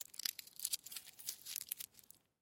Звуки фольги - скачать и слушать онлайн бесплатно в mp3
Шелест фольги